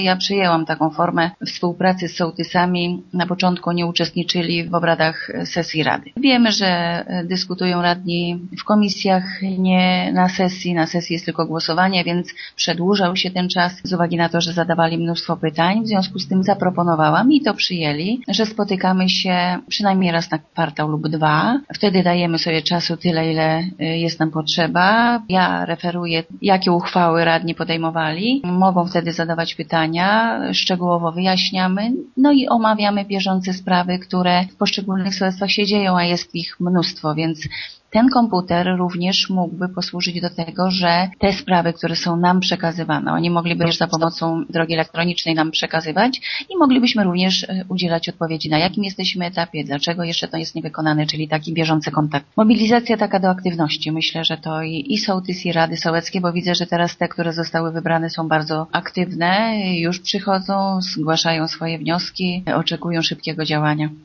Dlatego burmistrz Jaworska już zapowiada, że gmina będzie się starać o środki na realizację kolejnego etapu projektu e-sołtys. Jeśli otrzyma pieniądze kupi sołtysom komputery i zapewni im dostęp do internetu, dzięki czemu będą oni mogli mieć większy wpływ na podejmowane przez samorząd decyzje: